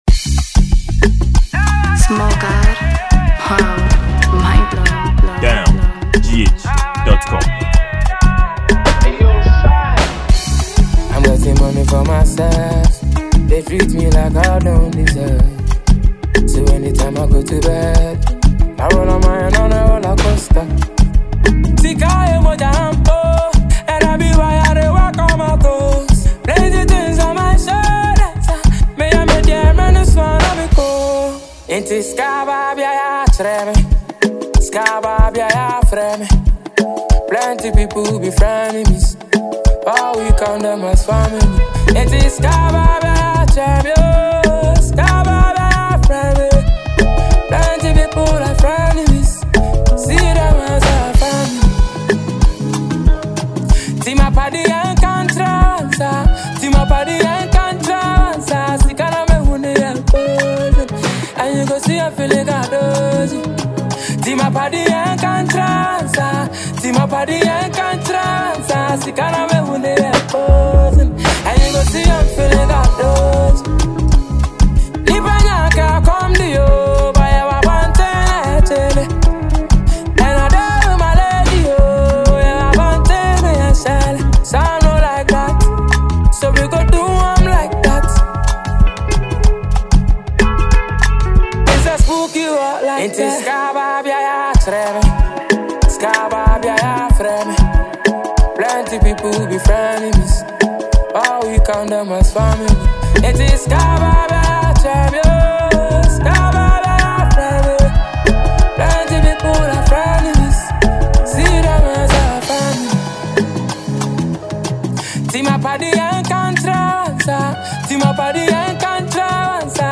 afrobeats producer
highlife